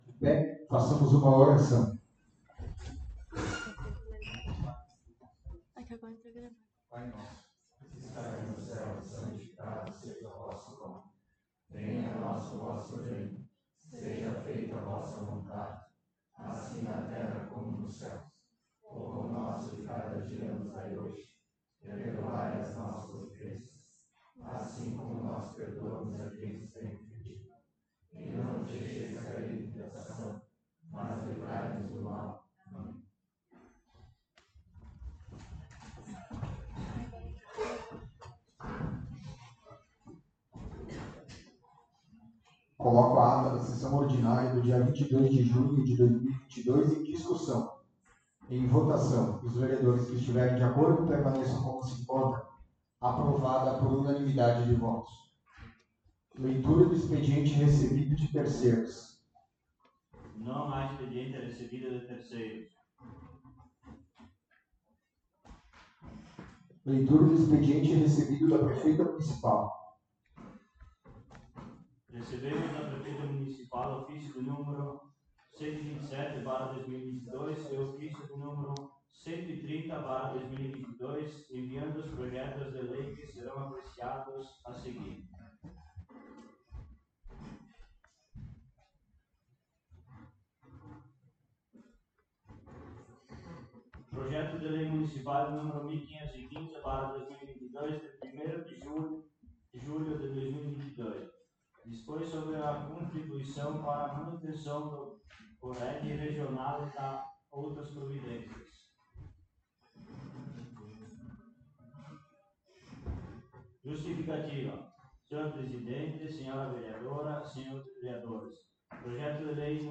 11ª Sessão Ordinária de 2022
Local: Câmara Municipal de Vereadores de Santa Tereza